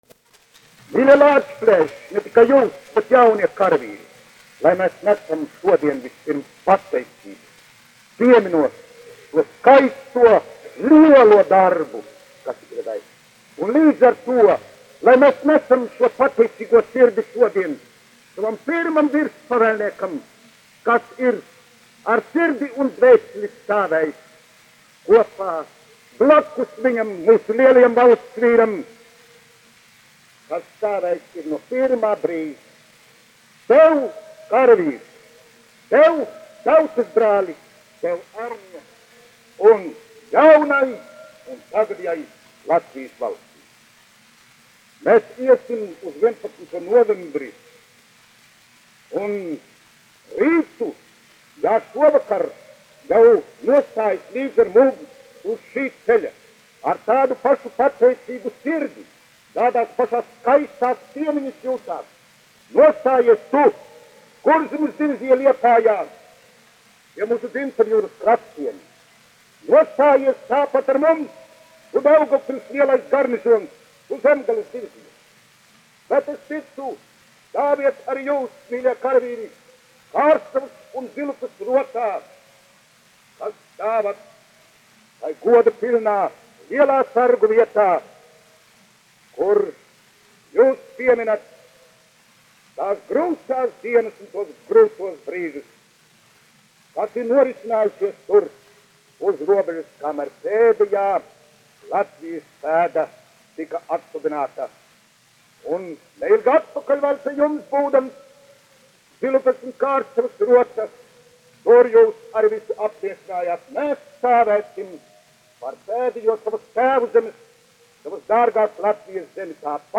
1 skpl. : analogs, 78 apgr/min, mono ; 25 cm
Luterāņu sprediķi
Skaņuplate